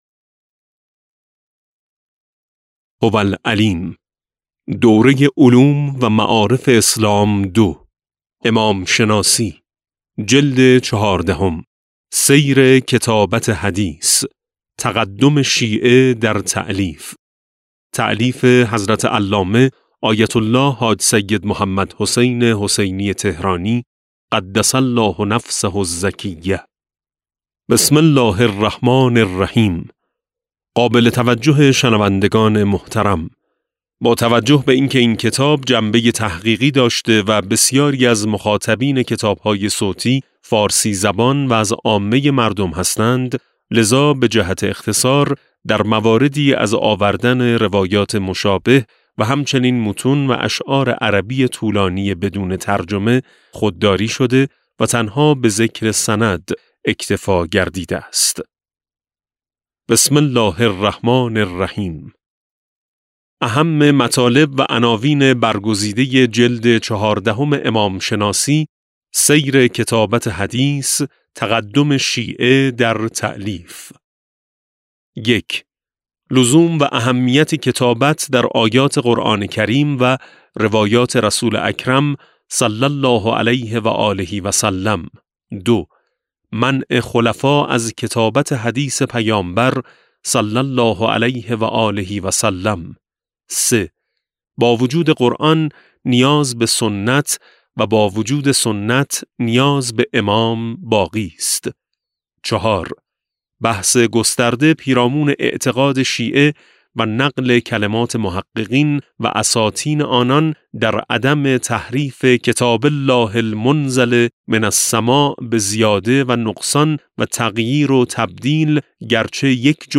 کتاب صوتی امام شناسی ج14 - جلسه1